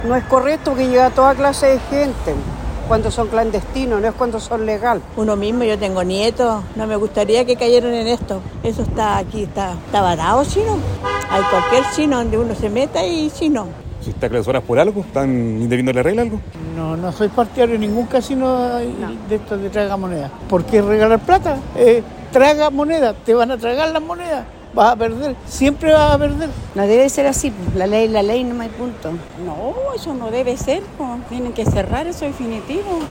Radio Bío Bío preguntó a los transeúntes sobre esta situación, quienes expresaron que los estos locales son focos de delitos, ludopatía e infracción a la ley.